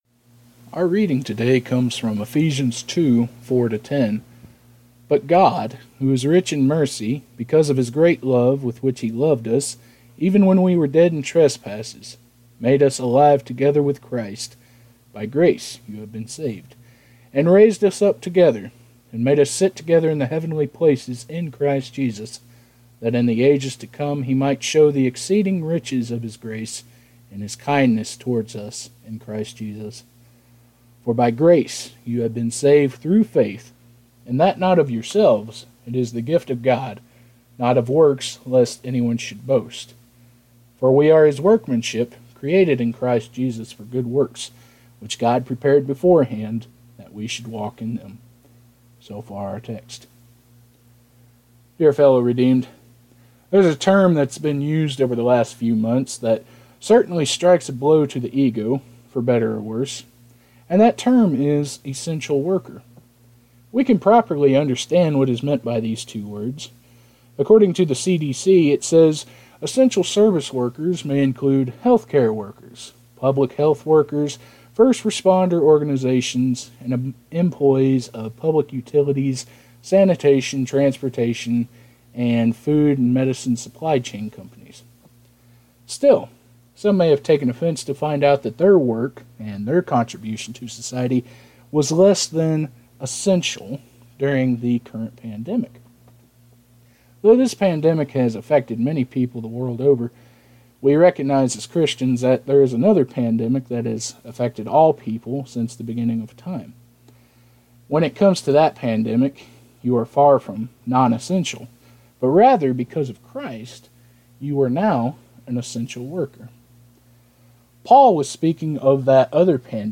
2020 Chapels -